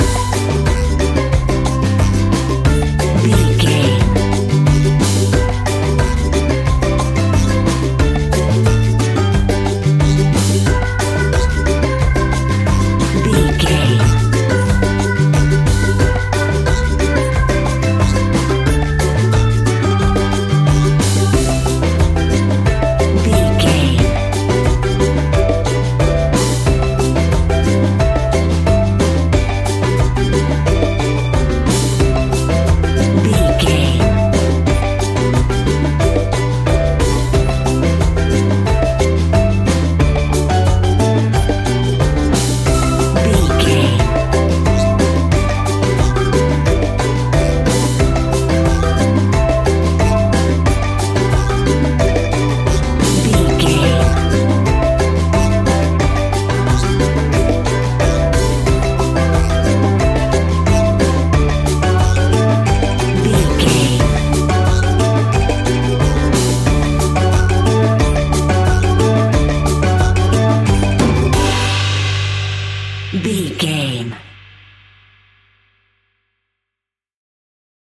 A groovy and upbeat piece of island summer sunshine music.
That perfect carribean calypso sound!
Uplifting
Ionian/Major
steelpan
worldbeat
drums
percussion
bass
brass
horns
guitar